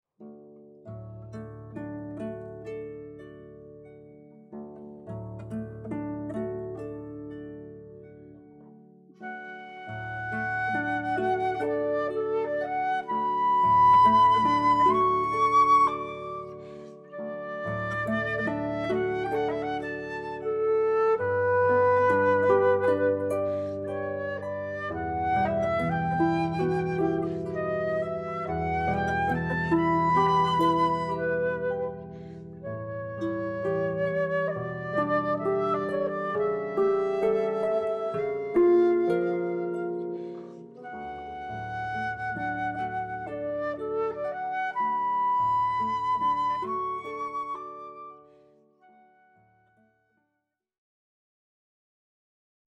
Méditation de Thaïs – Massenet – Flute et harpe
6-Méditation-Thaïs-Massenet-Flute-et-harpe.mp3